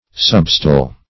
substile - definition of substile - synonyms, pronunciation, spelling from Free Dictionary
Substile \Sub"stile`\, n. (Dialing)